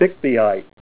Help on Name Pronunciation: Name Pronunciation: Bixbyite
Say BIXBYITE